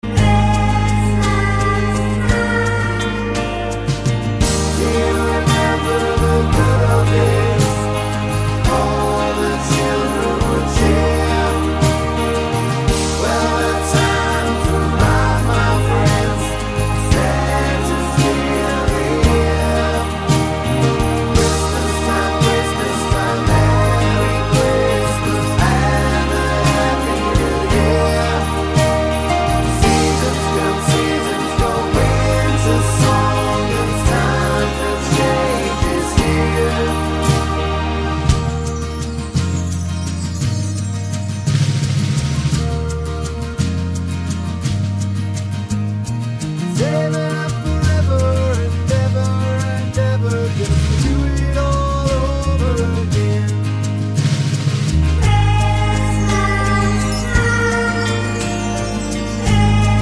no Backing Vocals Christmas 3:29 Buy £1.50